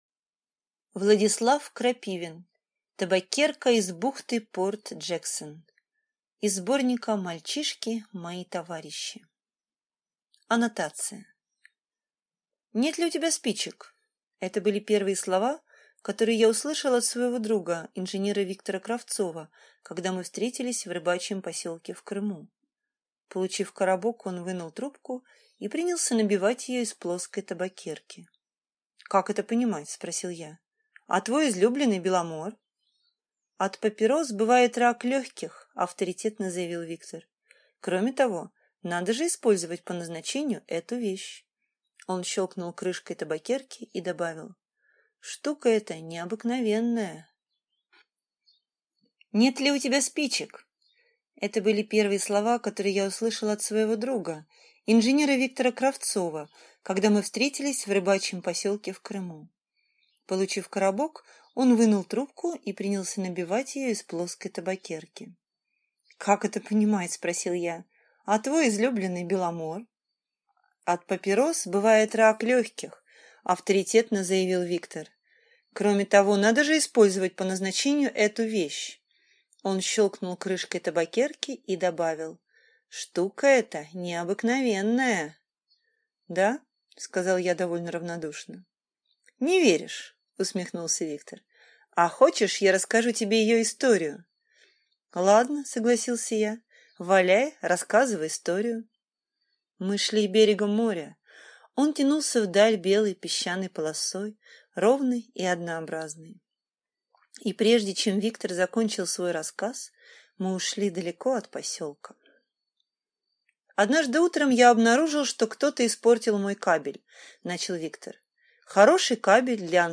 Аудиокнига Табакерка из бухты Порт-Джексон | Библиотека аудиокниг